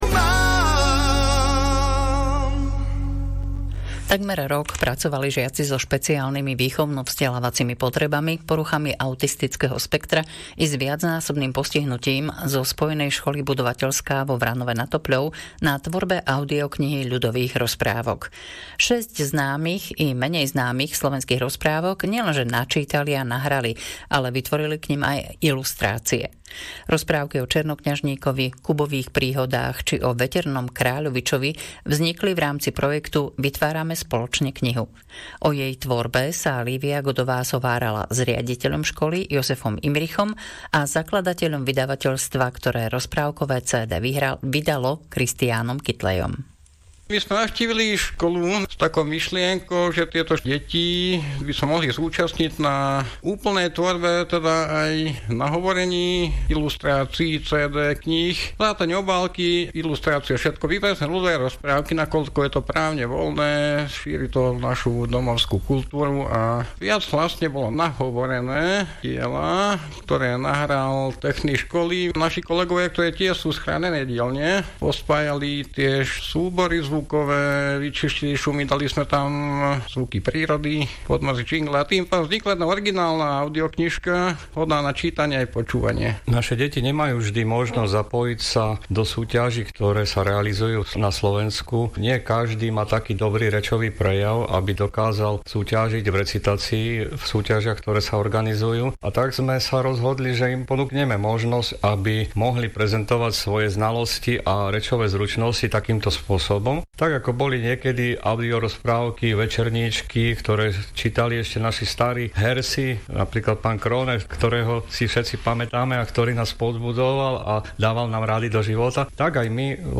RTVS Rádio Regina východ vysielanie